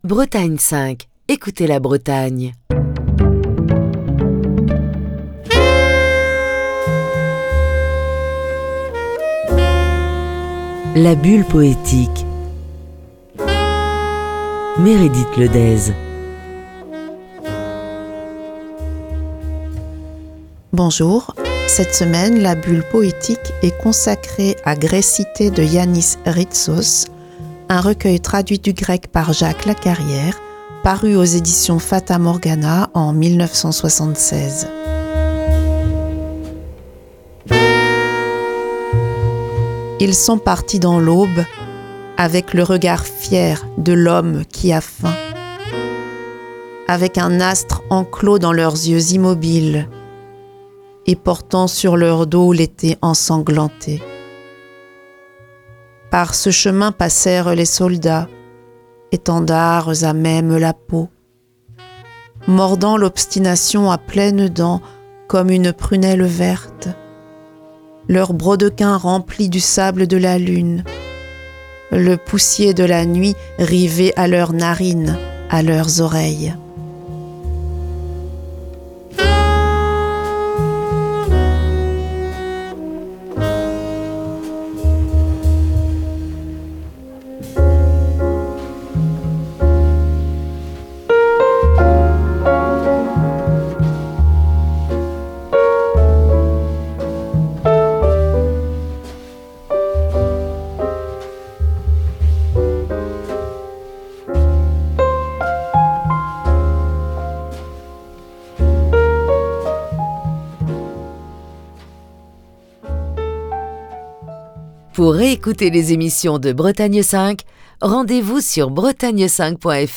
lit cette semaine des textes du poète grec Yánnis Rítsos